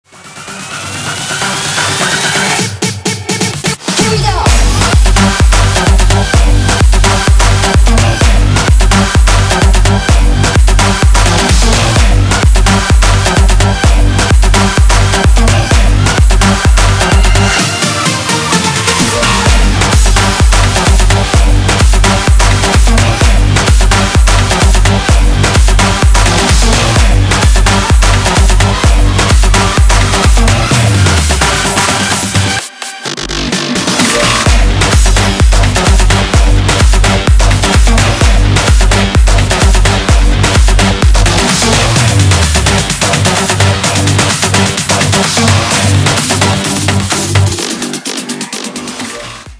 • Качество: 320, Stereo
progressive house
не плохой клубняк